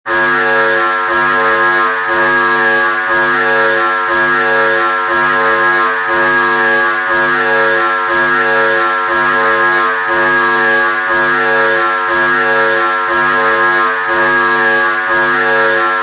Sound Effects
Alarm 1 With Reverberation